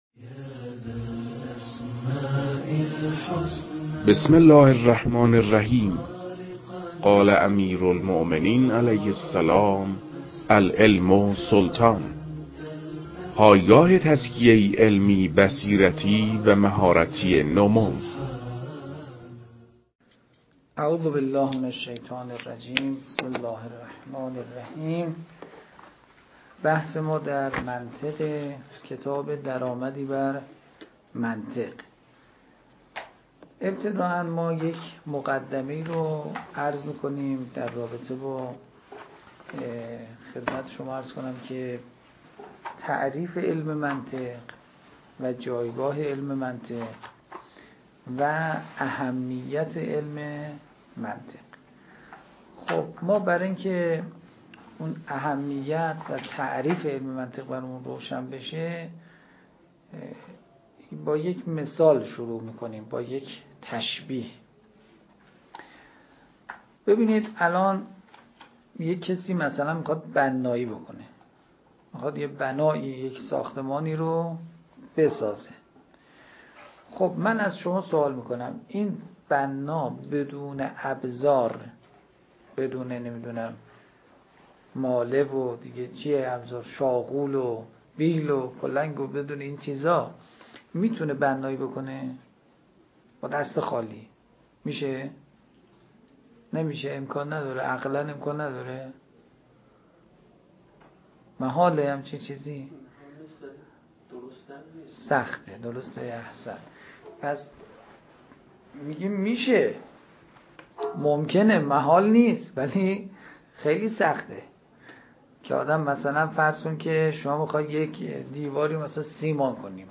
در این بخش، کتاب «درآمدی بر منطق» که اولین کتاب در مرحلۀ آشنایی با علم منطق است، به صورت ترتیب مباحث کتاب، تدریس می‌شود.
در تدریس این کتاب- با توجه به سطح آشنایی کتاب- سعی شده است، مطالب به صورت روان و در حد آشنایی ارائه شود.